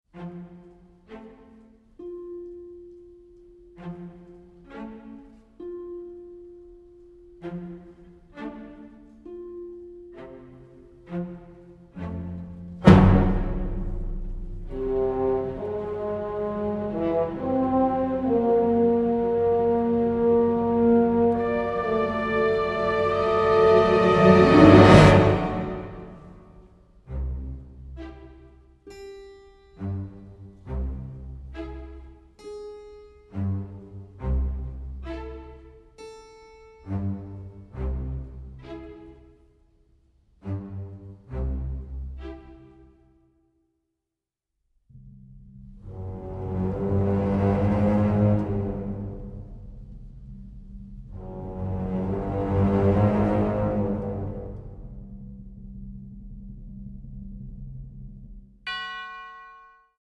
symphonic, varied, funny and emotional